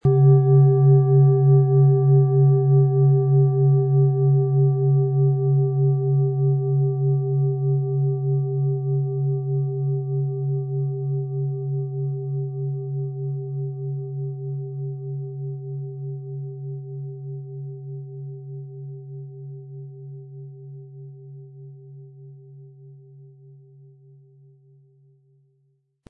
OM Ton
Handgearbeitete tibetische Schale mit dem Planetenton OM-Ton.
Um den Original-Klang genau dieser Schale zu hören, lassen Sie bitte den hinterlegten Sound abspielen.
MaterialBronze